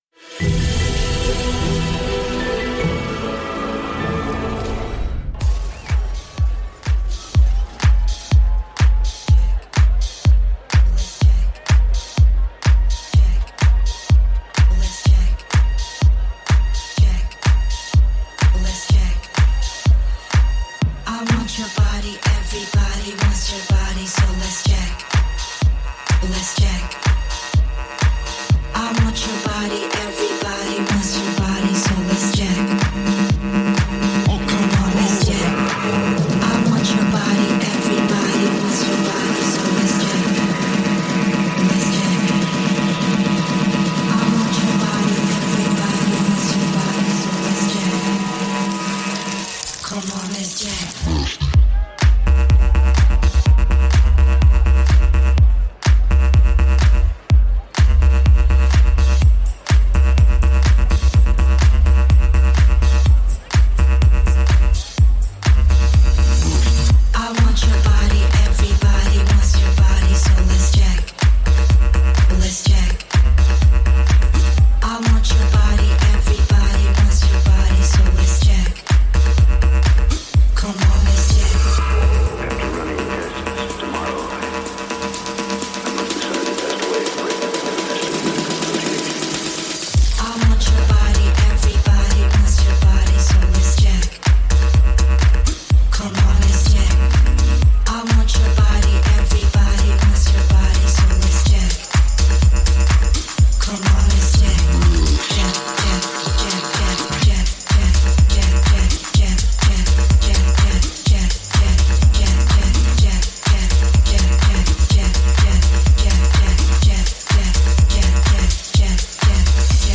Also find other EDM Livesets, DJ
Liveset/DJ mix